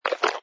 sfx_slurp_bottle02.wav